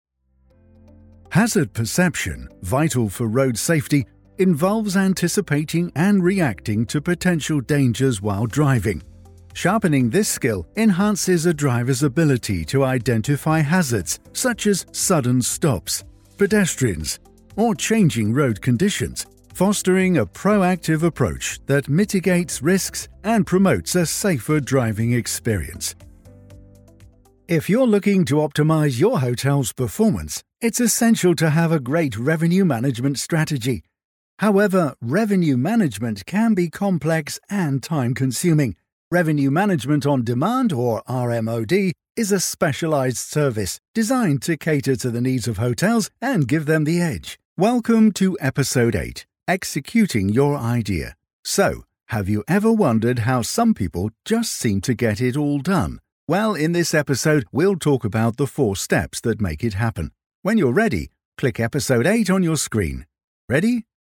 English (British)
Versatile, Reliable, Corporate, Mature, Natural
Explainer